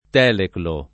[ t $ leklo ]